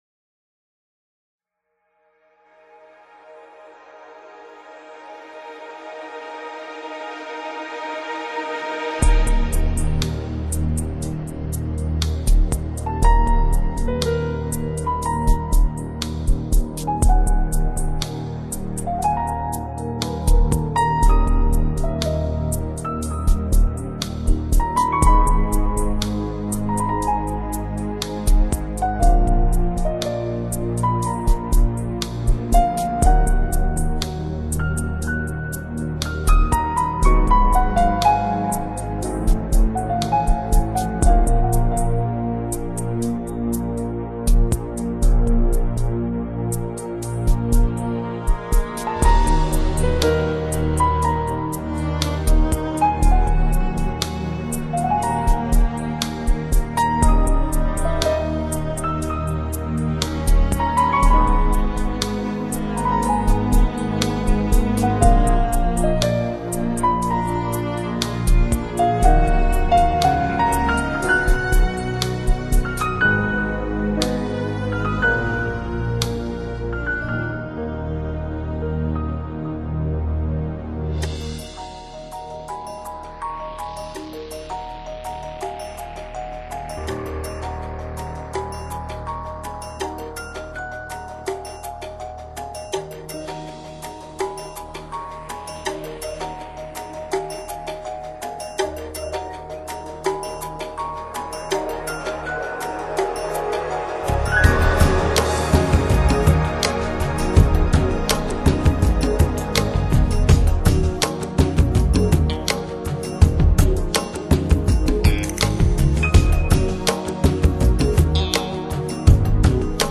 更进一步地，这首已经显得非常诡秘及忧郁，描画出了镜中的“灵魂”。
轻柔的钢琴，以及结尾处一段快速的音阶，最初听时在人的脑海中挥之不去。